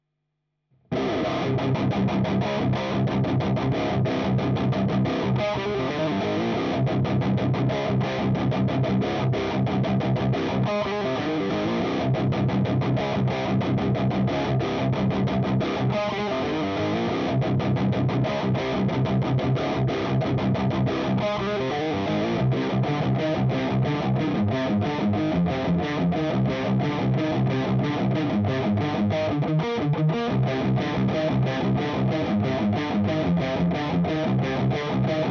Voici 4 sample de chaque canal en Vintage/Modern.
J'ai essayé d'être le plus rigoureux sur le positionnement des potars afin que les deux canaux soient identiques, mais pour être sûr de bien sentir la différence entre le Red et le Orange, et partant du principe que vous connaissez les différence de volume entre les canaux, j'ai mis le Red à un Volume équivalent à l'Orange.
L'Orange mode Vintage :
Gain 15h